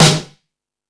Lazer Drums(17).wav